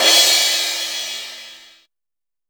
CRASH 900.WAV